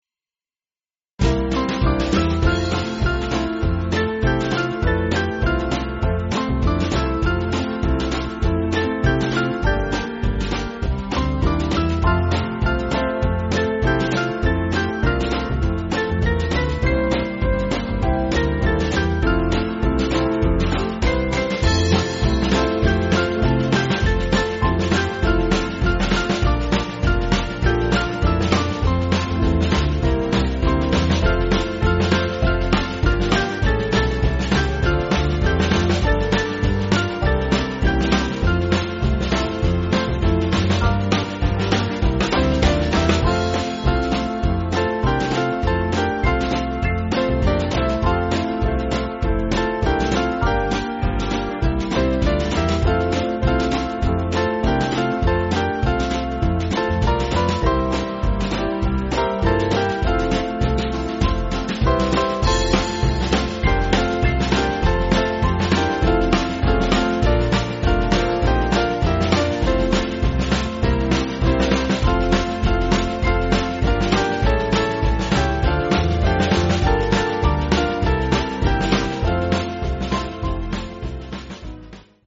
Small Band
(CM)   3/F-Gb